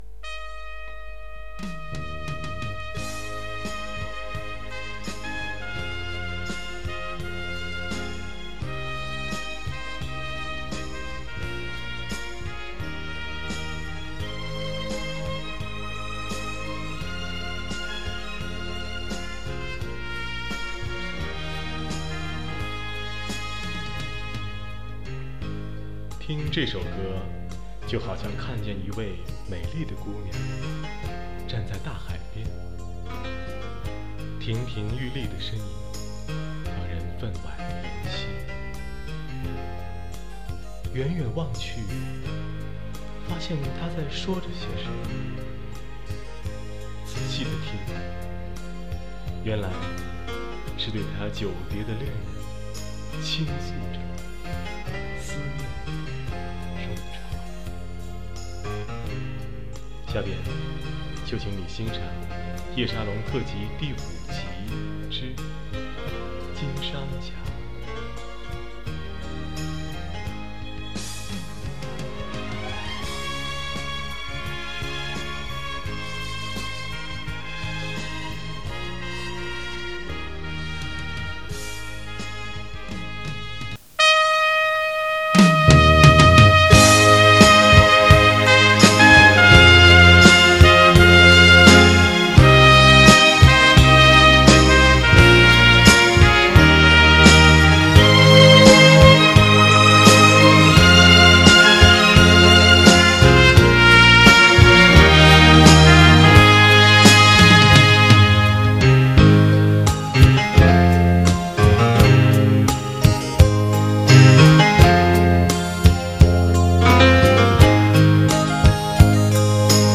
中国现代舞曲音乐的
慢四
日本歌曲
主奏乐器
小号
键盘
和声乐器
小提琴组
伴唱